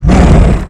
icestorm.wav